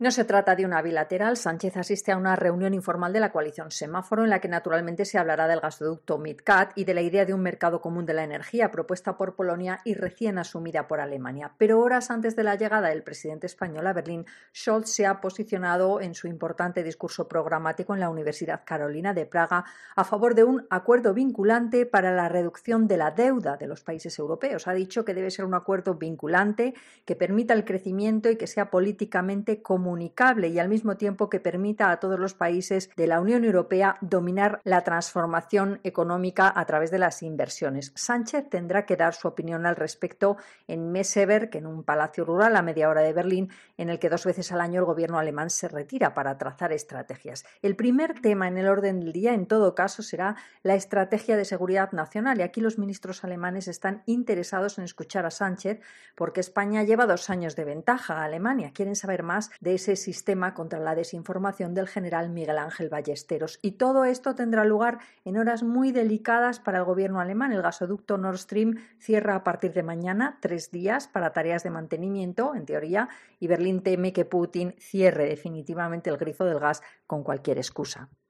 corresponsal en Berlín, explica la reunión del Gobierno alemán